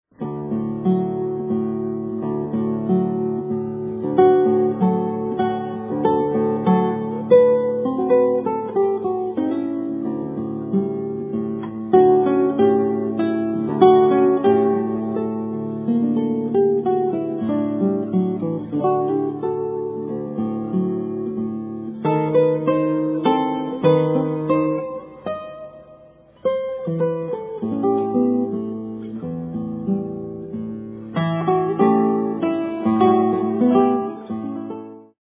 MODERN GUITAR